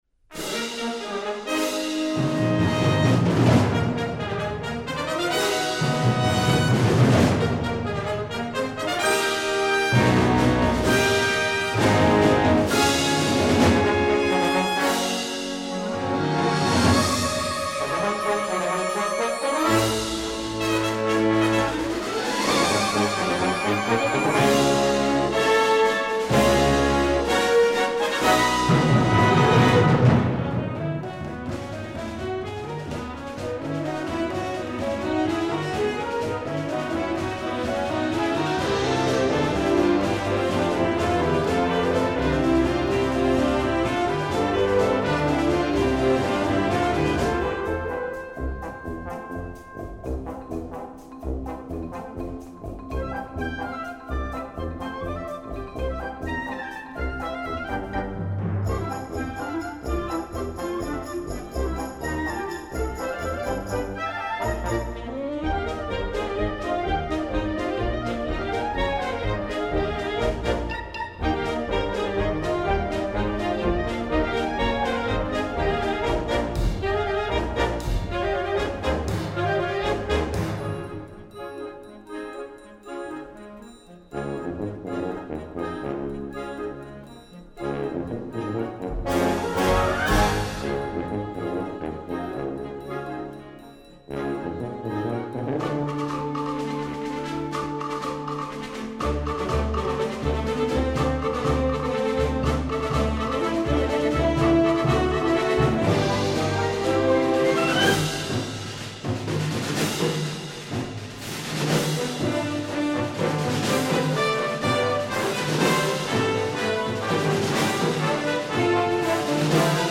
folk, new age, contemporary, children, instructional, sacred